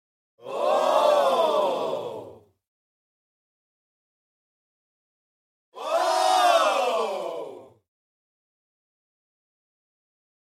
SFX喔明白了 一群人音效下载
SFX音效